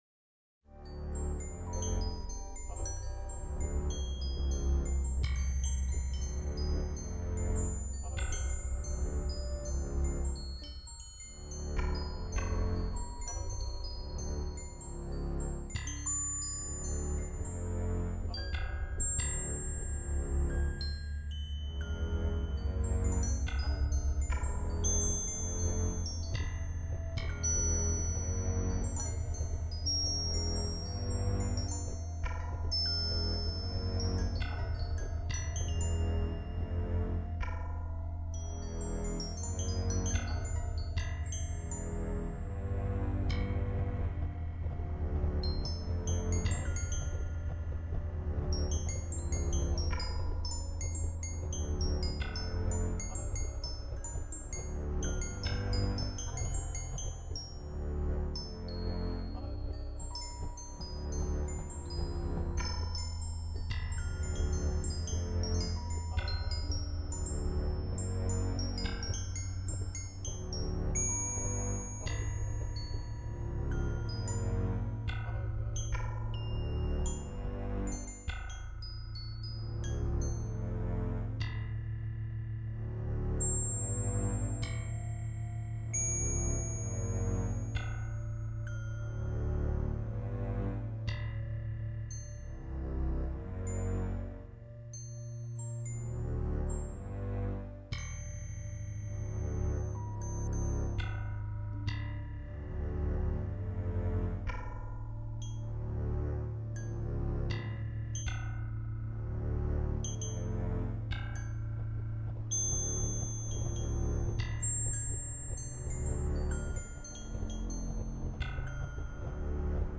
Background music with some sound effects